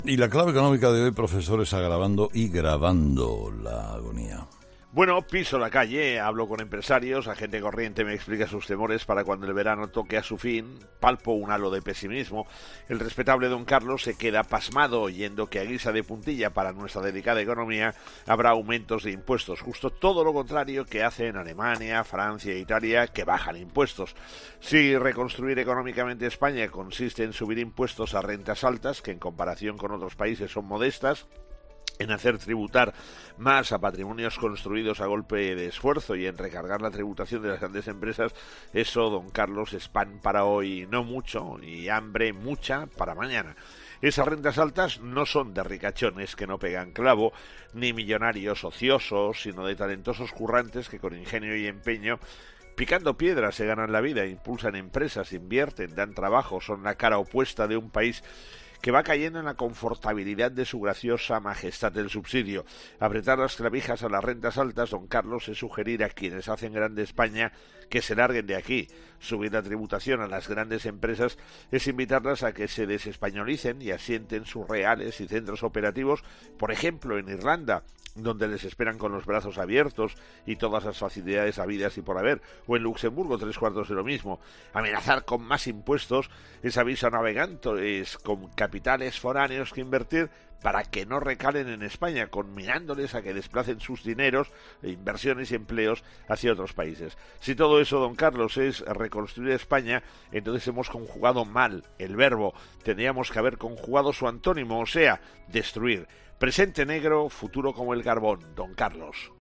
El profesor José María Gay de Liébana analiza en 'Herrera en COPE’ las claves económicas del día